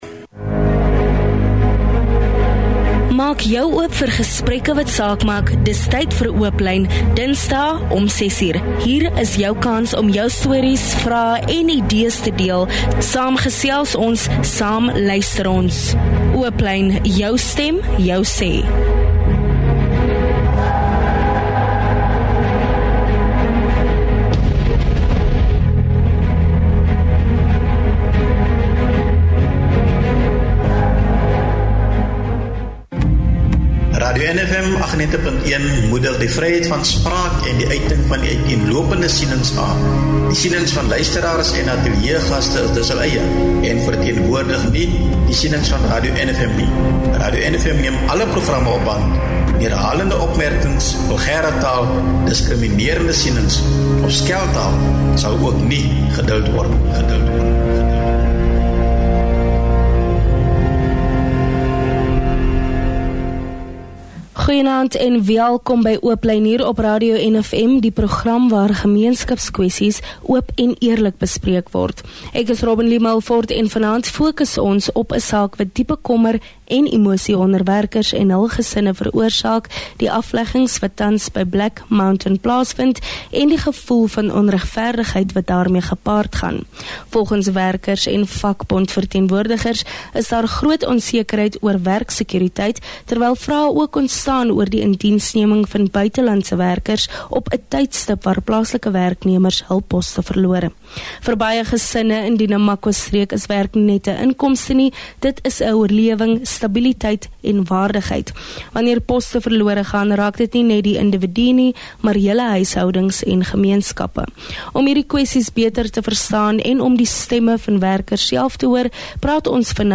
Werkers en vakbondverteenwoordigers spreek hul kommer uit oor toenemende werksonsekerheid en ontstellende vrae rondom die indiensneming van buitelandse werkers, terwyl plaaslike werknemers hul lewensbestaan verloor. In die Namakwa-streek is werk meer as net ’n inkomste; dit verteenwoordig oorlewing, stabiliteit en waardigheid.